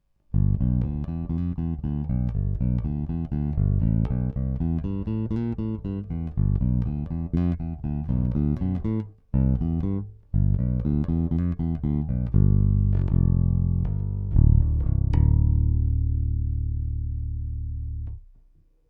Snímač krk, jednocívka (prsty)